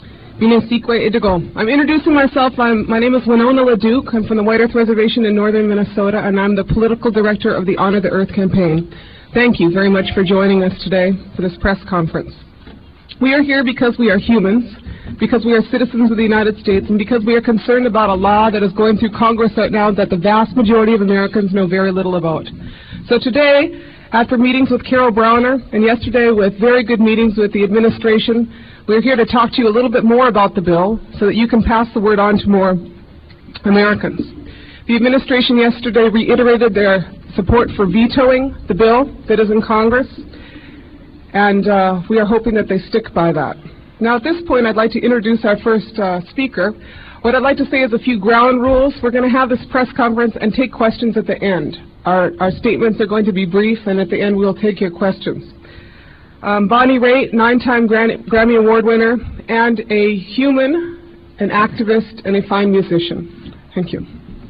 lifeblood: bootlegs: 1997-09-24: honor the earth press conference - washington, d.c.
01. press conference - winona laduke (1:04)